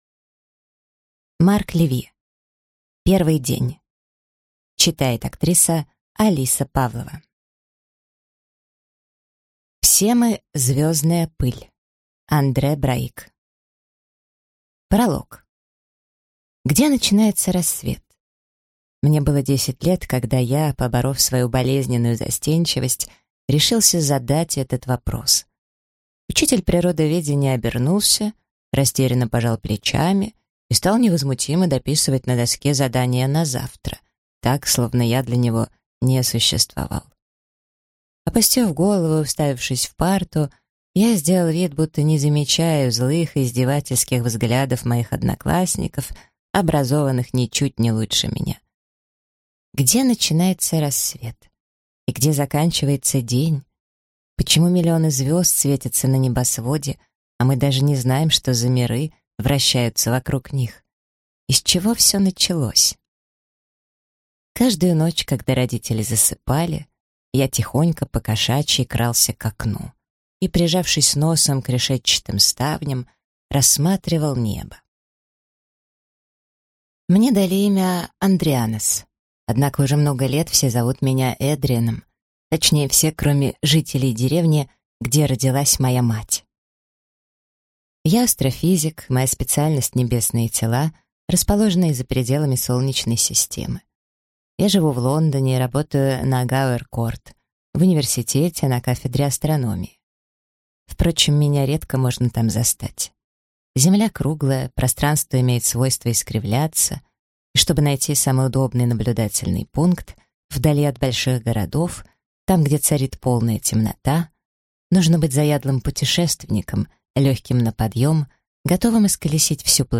Аудиокнига Первый день | Библиотека аудиокниг